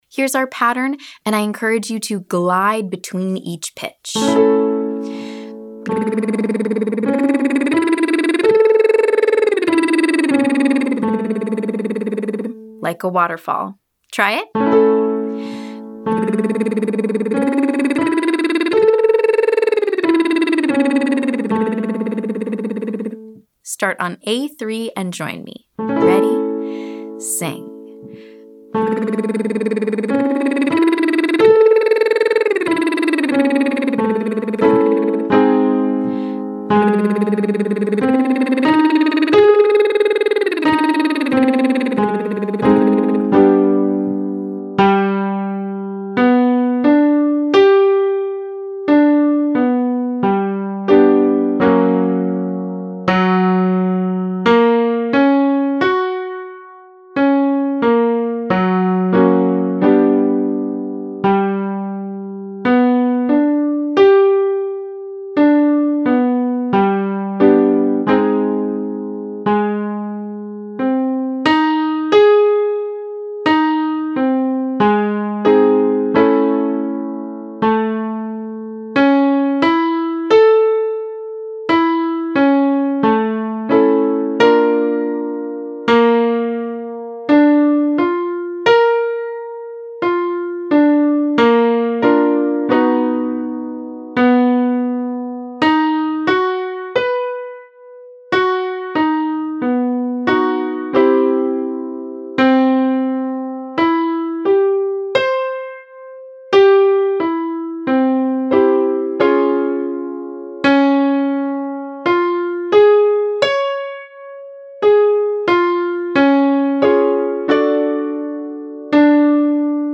Welcome - Online Singing Lesson
Exercise: Choose your favorite SOVT technique and stretch your range.